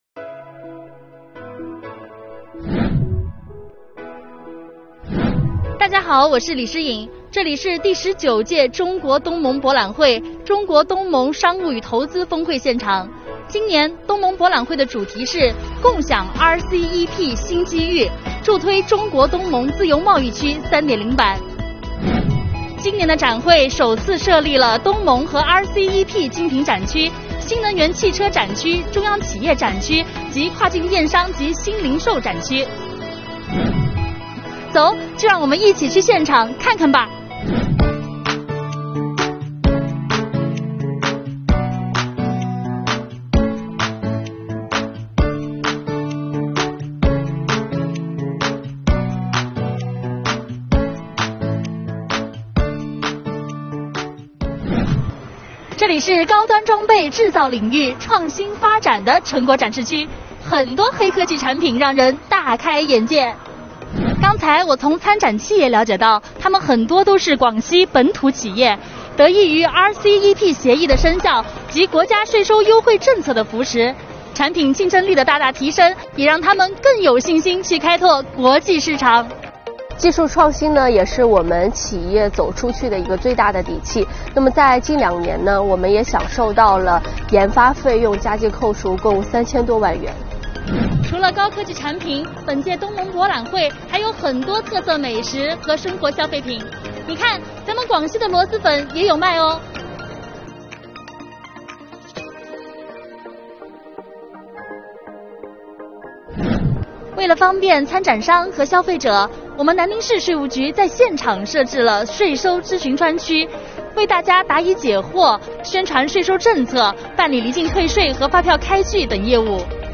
Vlog丨走，带你去逛“东博会”